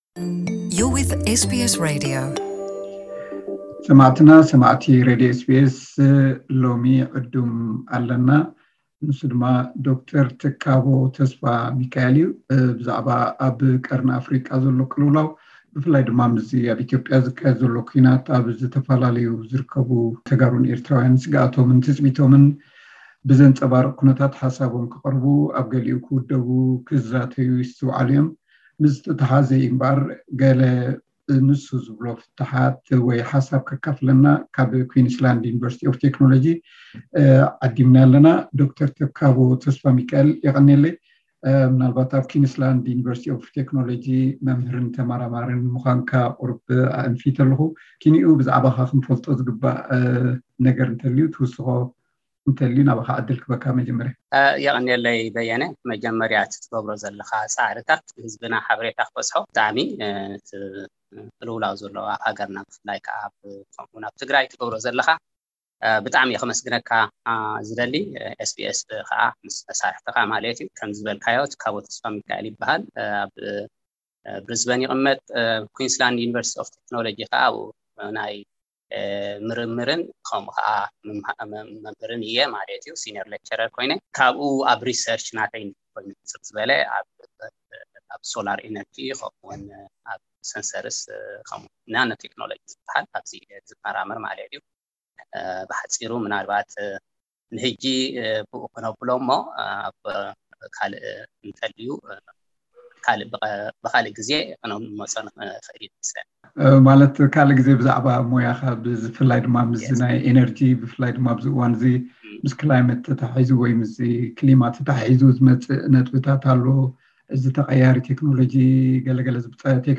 ኣብ ኢትዮጵያ ዝካየድ ዘሎ ኲናት ተተሓሒዙ፡ ኣብዝተፈላለዩ ዝርከቡ ተጋሩን ኤርትራዊያንን ስግኣቶም ትጽቢቶምን ብዘንጸባርቕ ኩነታት ሓሳቦም ከቕርቡ ኣብ ገሊኡ ክውደቡን ክዛተዩን ይስትውዓሉ’ዮም። ብዛዕባዚ ስግኣቱን ገለ ፍታሓትን ዝበሎ ሓሳብ ቀዳማይ ክፋል ቃለ መሕትት